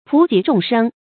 普濟眾生 注音： ㄆㄨˇ ㄐㄧˋ ㄓㄨㄙˋ ㄕㄥ 讀音讀法： 意思解釋： 同「普度眾生」。